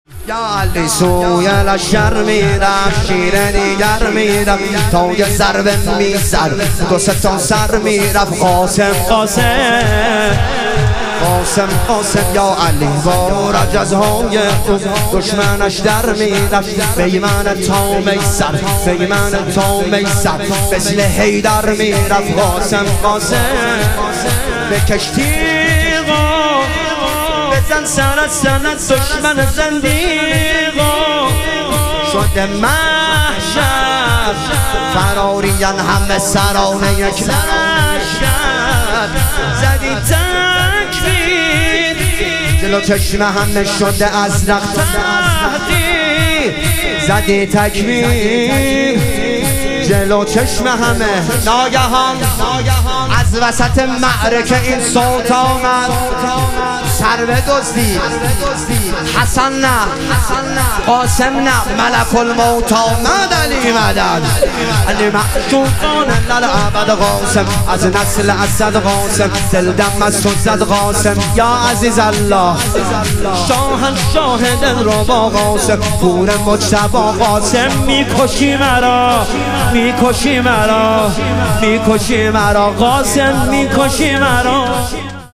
شهادت امام هادی علیه السلام - شور